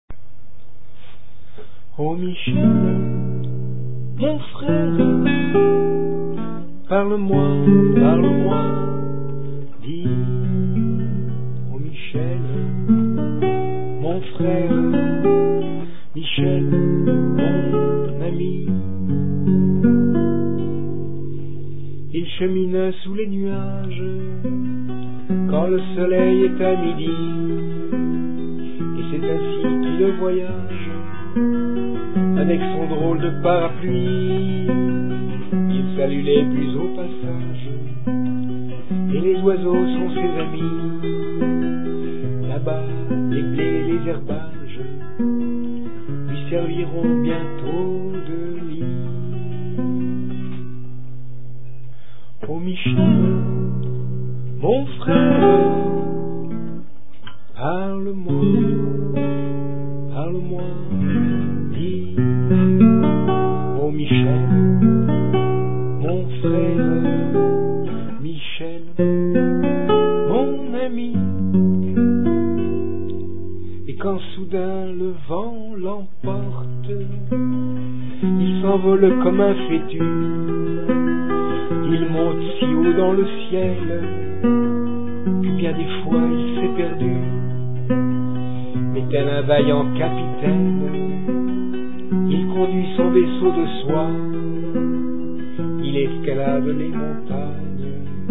Accord arpégés